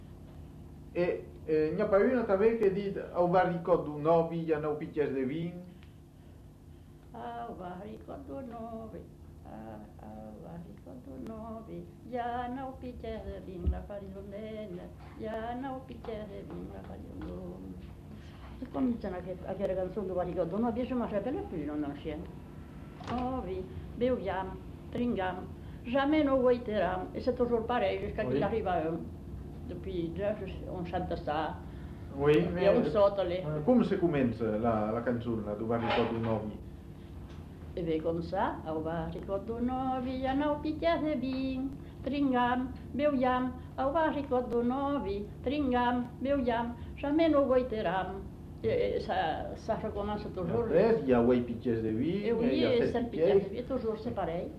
Aire culturelle : Marsan
Genre : chant
Effectif : 1
Type de voix : voix de femme
Production du son : chanté
Classification : chansons de neuf